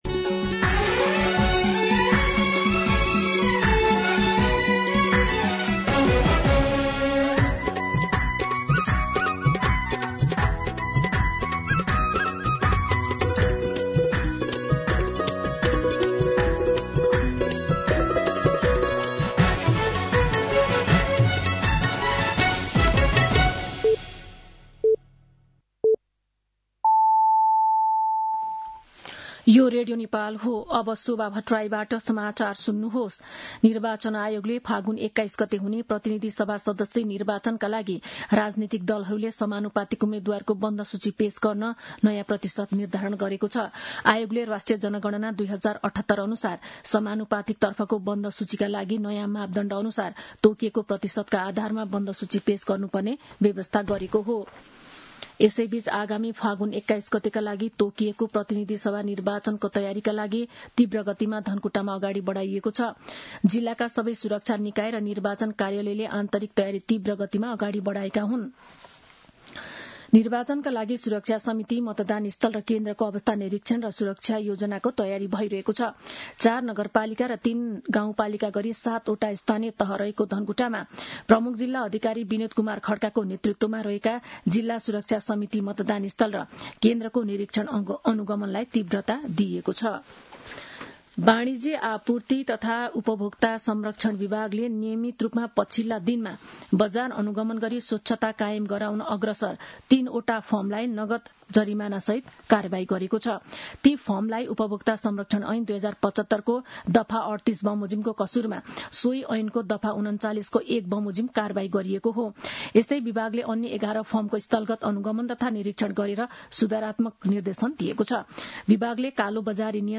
मध्यान्ह १२ बजेको नेपाली समाचार : ८ पुष , २०८२